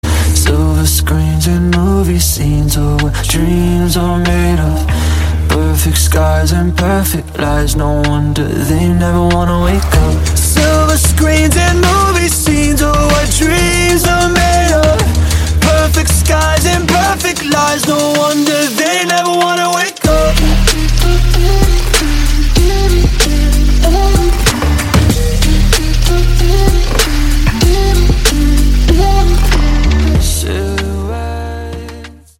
• Качество: 128, Stereo
красивый мужской голос
Electronic
house